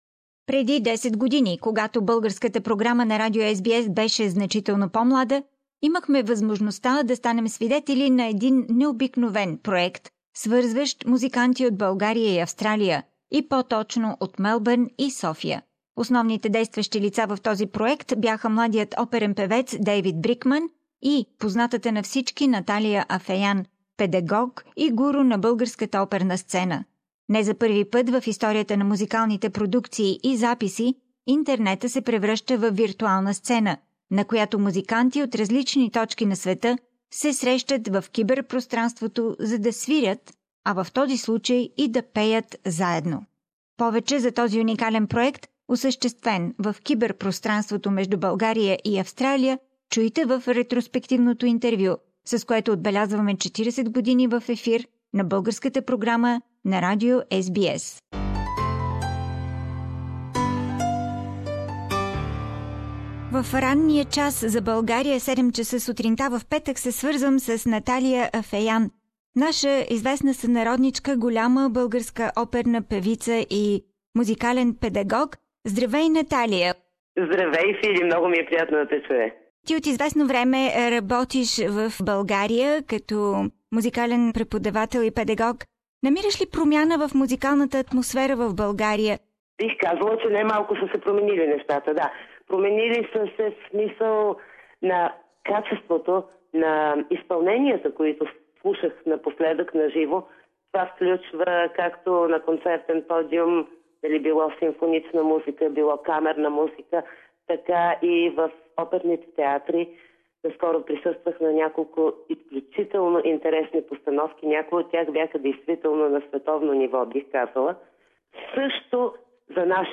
You can hear more about this unique project, carried out in cyberspace between Sofia and Melbourne, in our flashback interview marking the 40th anniversary of the Bulgarian program on SBS radio.